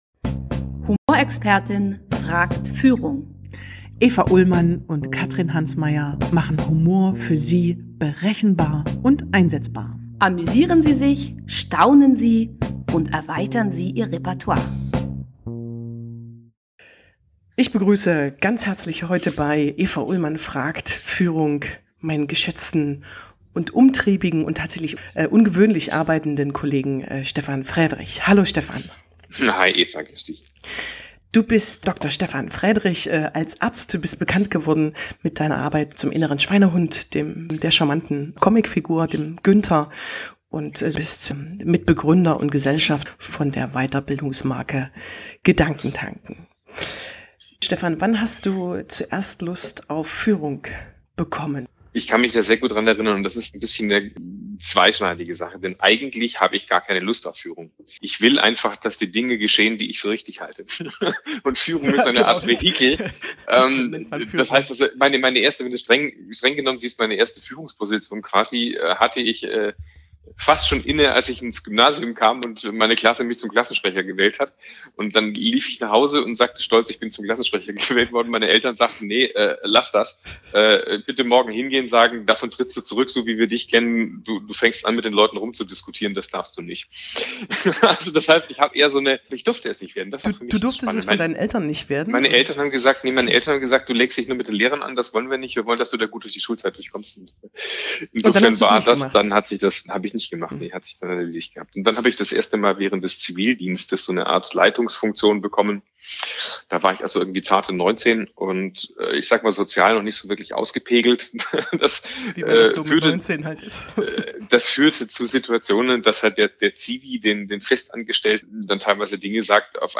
Das Interview fand im Februar 2020 statt.